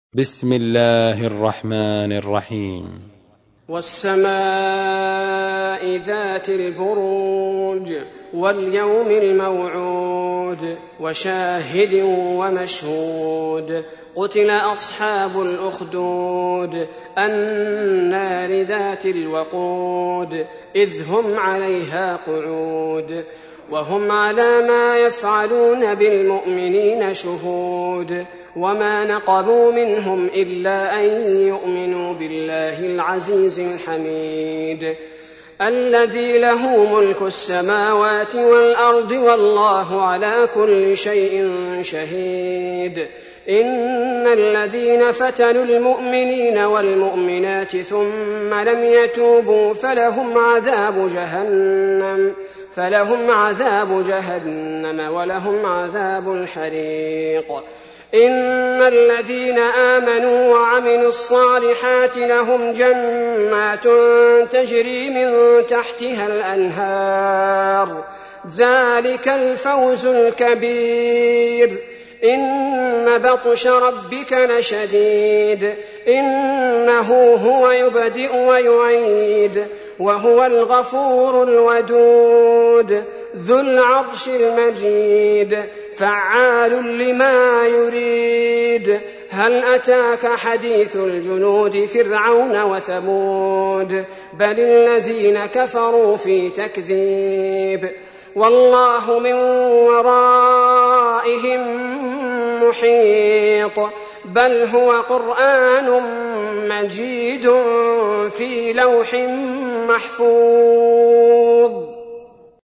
تحميل : 85. سورة البروج / القارئ عبد البارئ الثبيتي / القرآن الكريم / موقع يا حسين